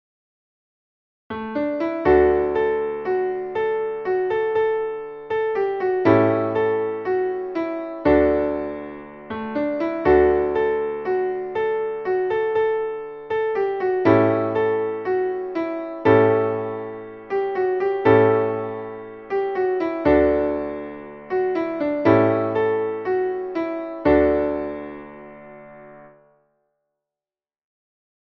Traditionelles Lied